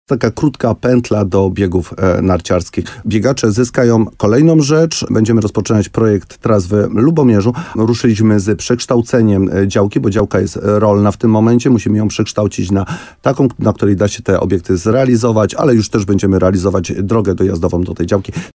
– W planie, poza boiskiem wielofunkcyjnym z Orlikiem, jest też plac zabaw i trasy biegowe – wyliczał wójt Mirosław Cichorz w programie Słowo za Słowo na antenie RDN Nowy Sącz.